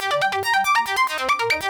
Index of /musicradar/shimmer-and-sparkle-samples/140bpm
SaS_Arp03_140-C.wav